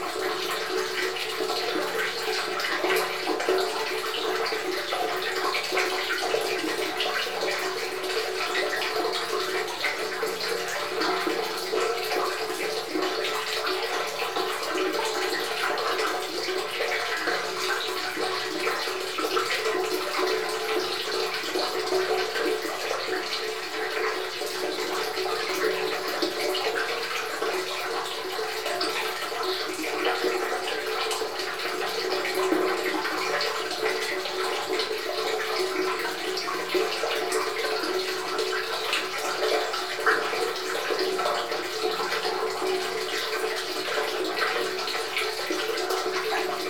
SND_sewage_environment_loop.ogg